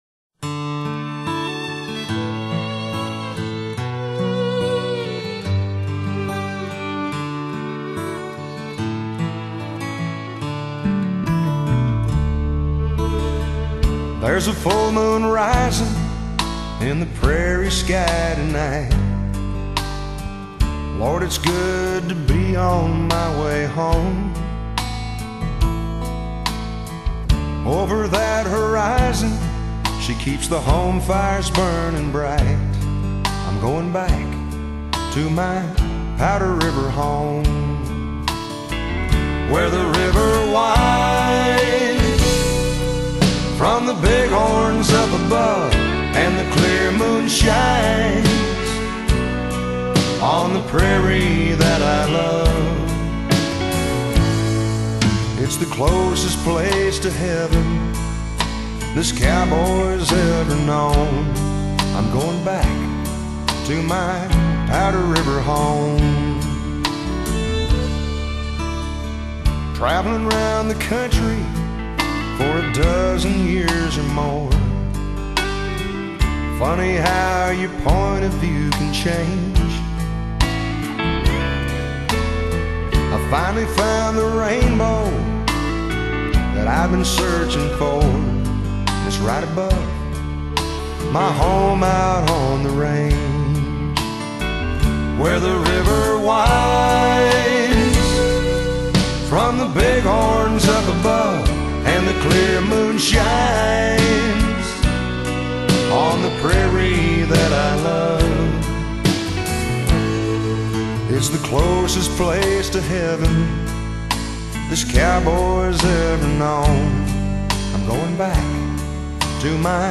聽他的歌曲有一種很鬆弛的感覺，醇厚本色的嗓音帶來原汁原味的鄉村氣息。